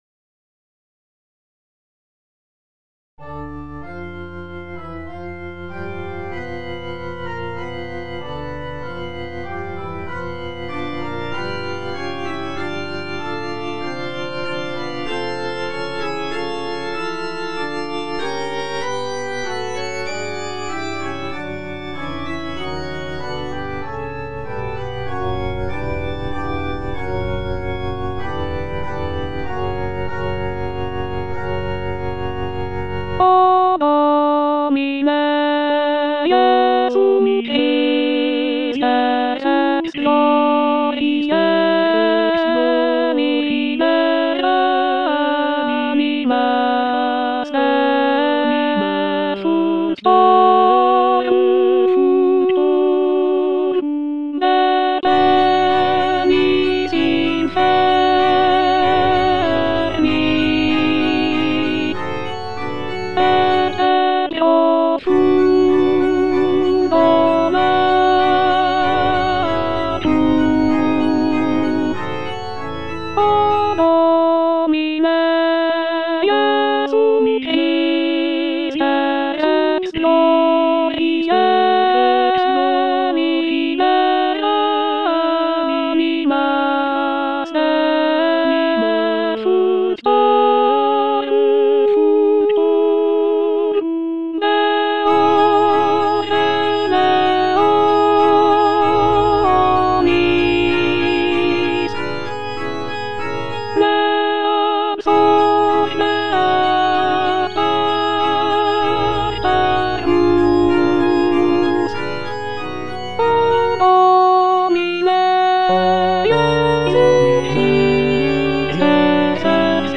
G. FAURÉ - REQUIEM OP.48 (VERSION WITH A SMALLER ORCHESTRA) Offertoire - Alto (Emphasised voice and other voices) Ads stop:
This version features a reduced orchestra with only a few instrumental sections, giving the work a more chamber-like quality.